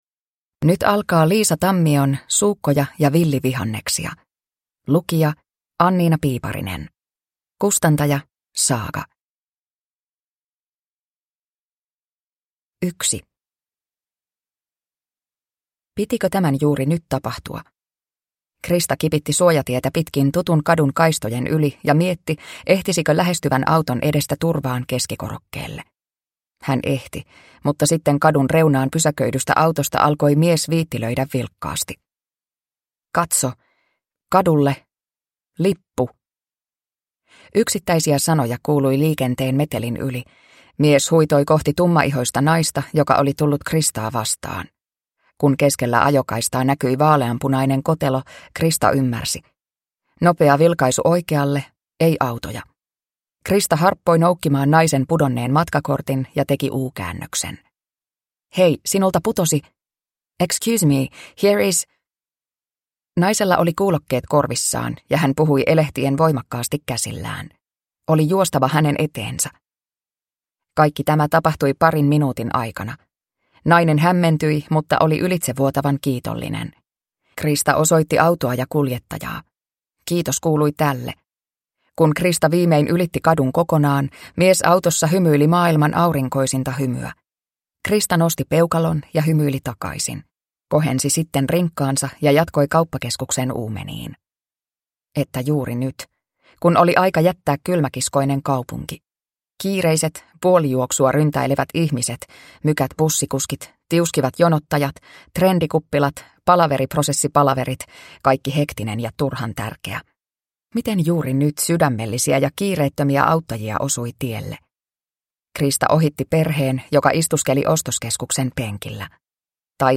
Suukkoja ja villivihanneksia – Ljudbok